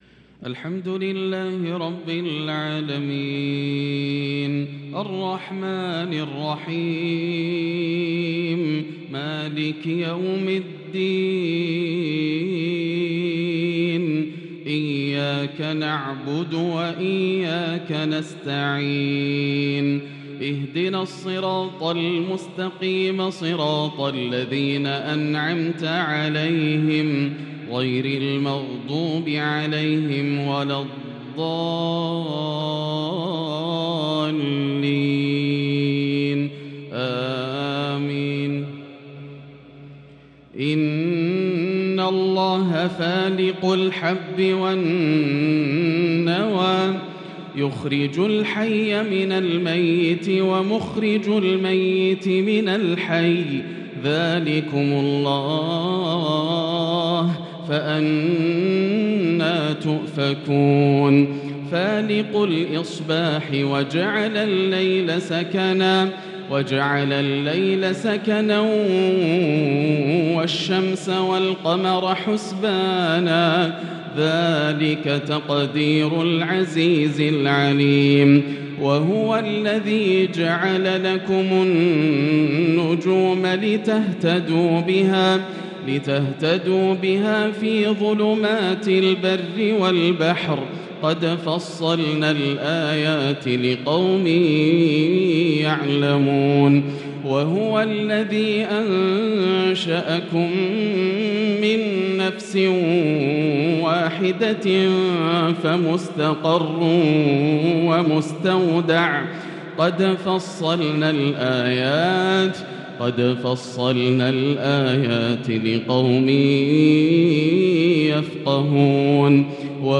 عشاء الإثنين 6-7-1443هـ من سورة الأنعام | Isha prayer from Surah Al-An'aam 7-2-2022 > 1443 🕋 > الفروض - تلاوات الحرمين